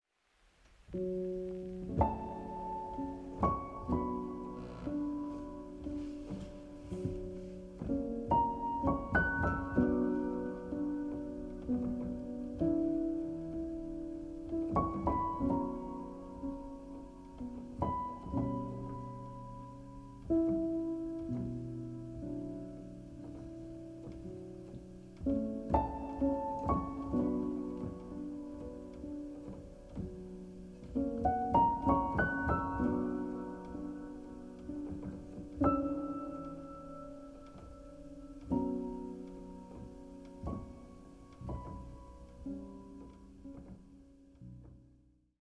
ポスト・クラシカル
一歩引いたところから自分を、世界を見る、静かな熱量。